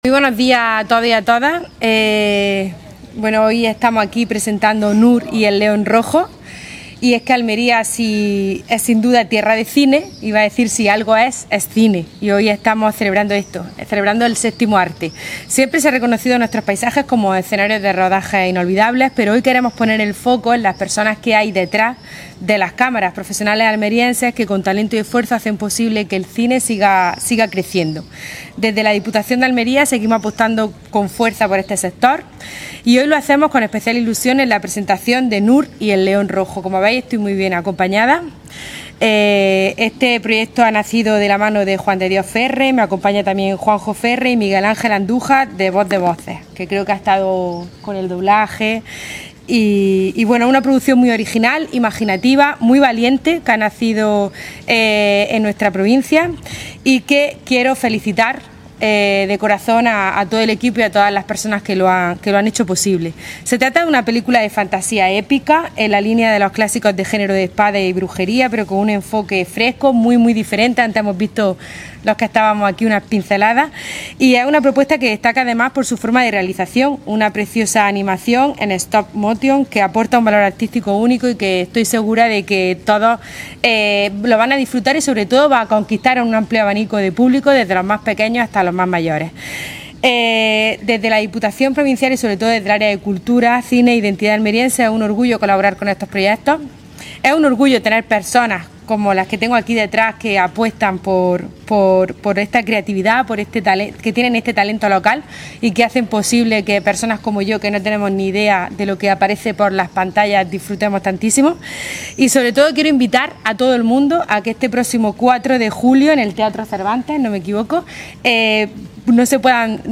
Brutos-audio-Presentacion-Nur-y-el-Leon-Rojo.mp3